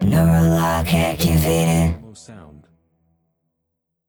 “Neural lock activated” Clamor Sound Effect
Can also be used as a car sound and works as a Tesla LockChime sound for the Boombox.
NeuralLockActivated-Demo.wav